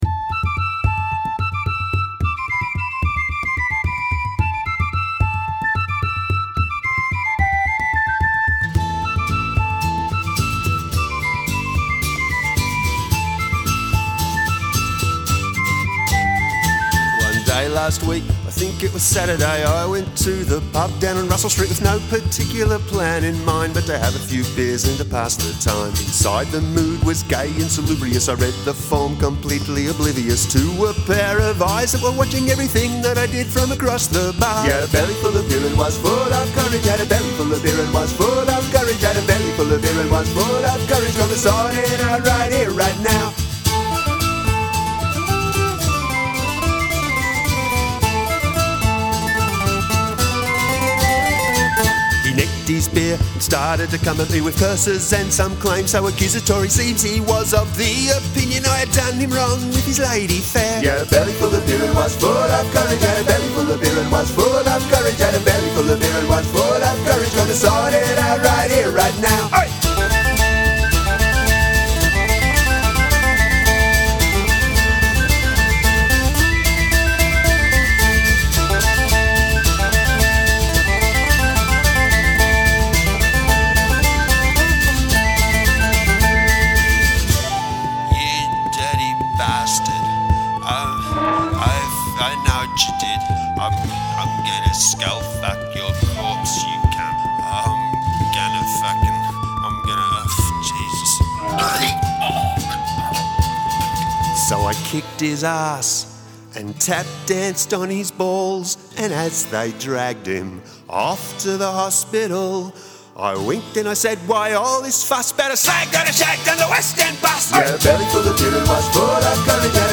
Must include a spoken word bridge